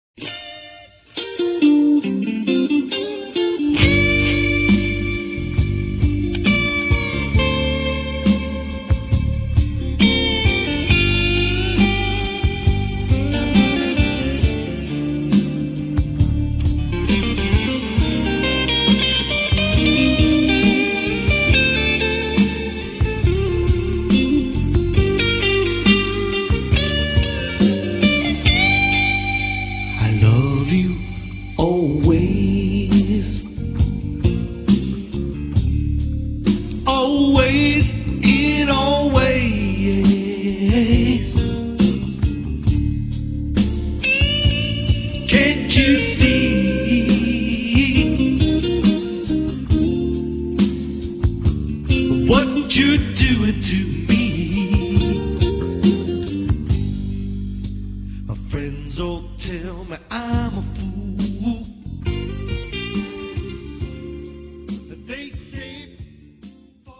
The cd is mixture of R & B grooves,
great percussion feels and very melodic vocals.